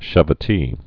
(shə-və-tē)